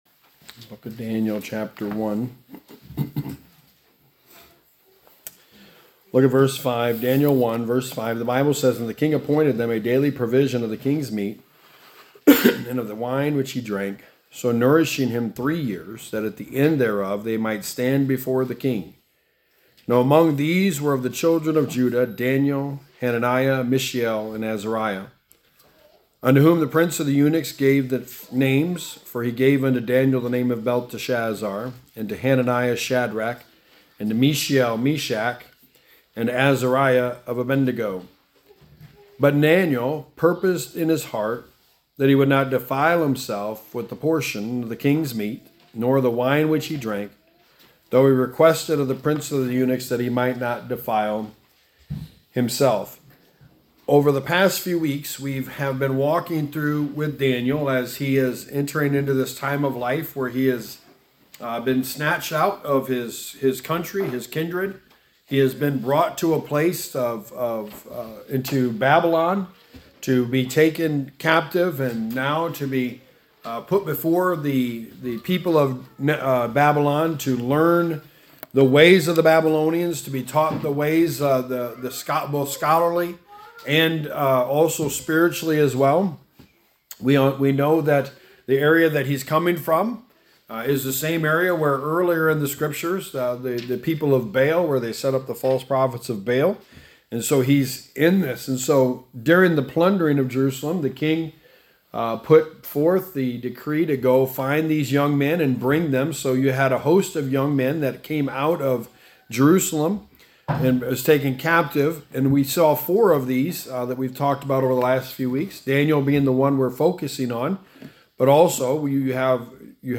Sermon #4: A Purposed Life – Controlling What You Can Control
Service Type: Sunday Morning